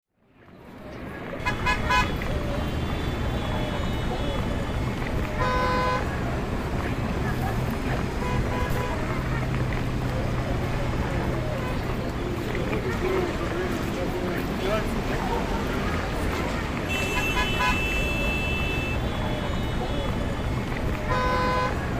Звуки городской улицы
На этой странице собраны разнообразные звуки городской улицы: гул машин, голоса прохожих, сигналы светофоров и другие фоновые шумы мегаполиса.
Шум на улице и проспекте